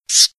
EntityDead.wav